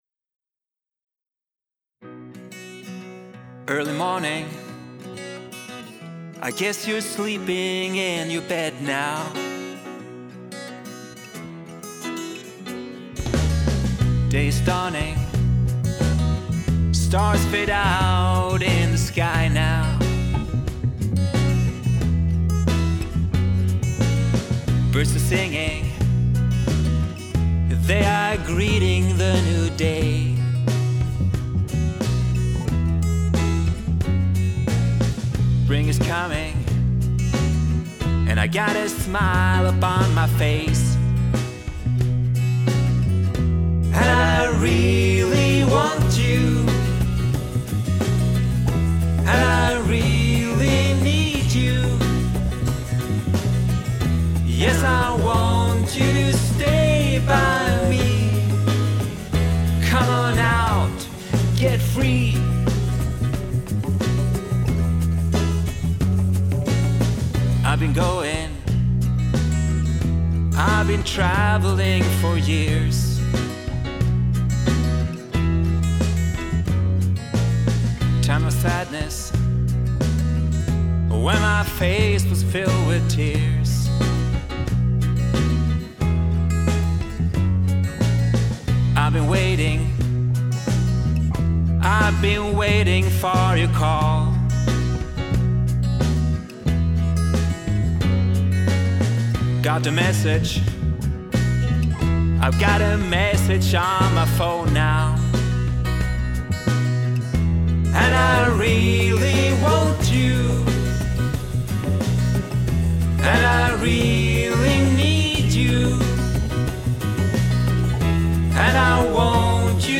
gitarr keyboard sång
stämsång